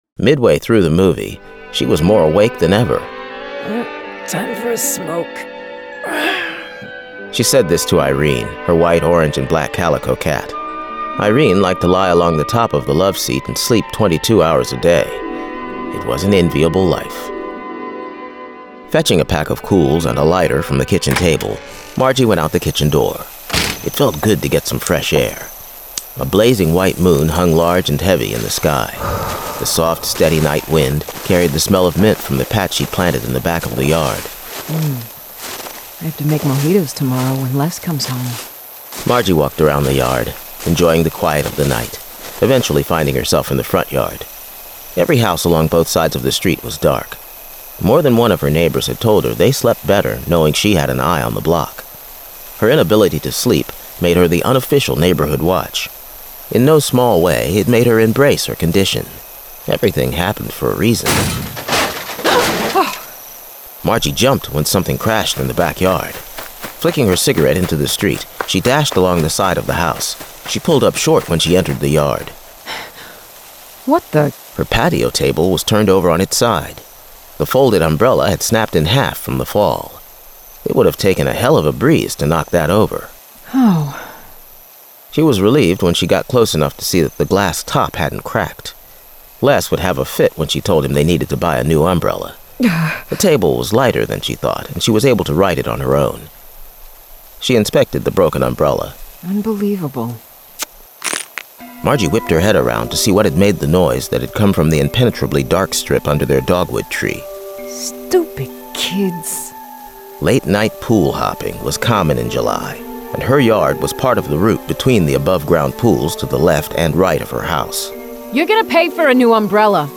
Full Cast. Cinematic Music. Sound Effects.
[Dramatized Adaptation]
Genre: Horror